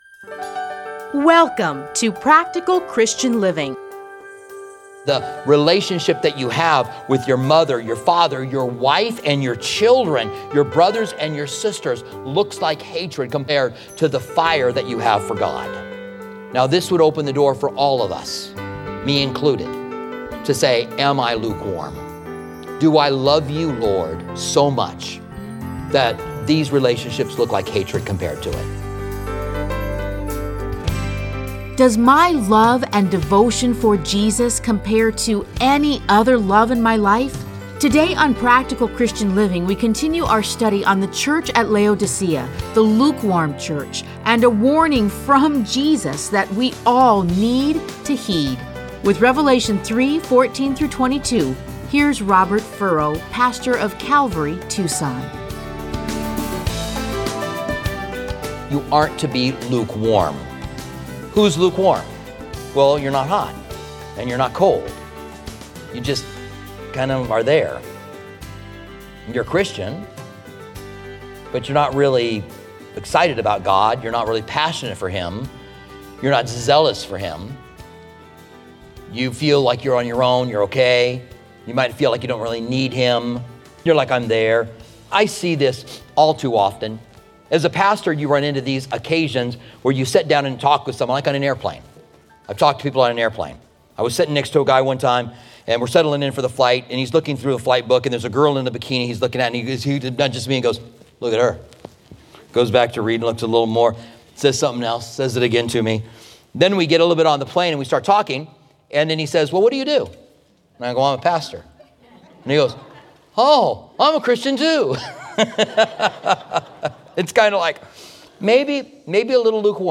Listen to a teaching from Revelation 3:14-22.